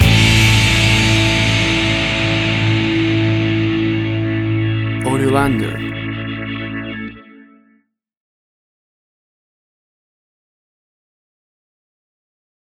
Classic American Blues from the deep south.
Tempo (BPM): 133